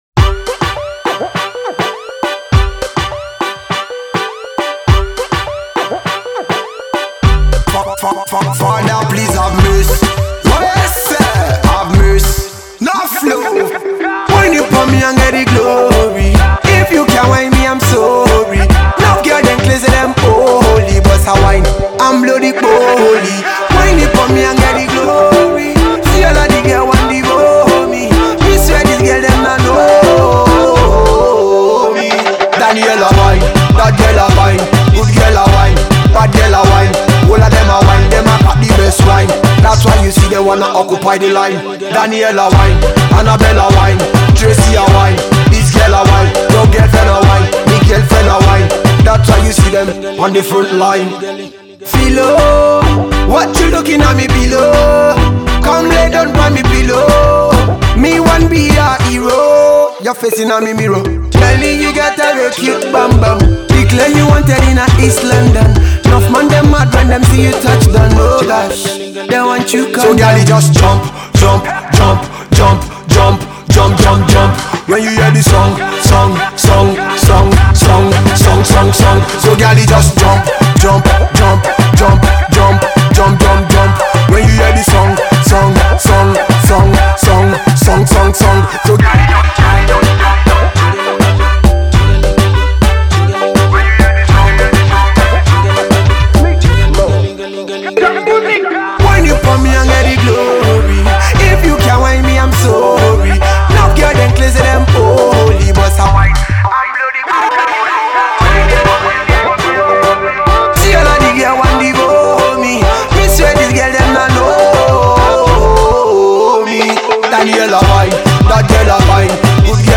is a classic dancehall tune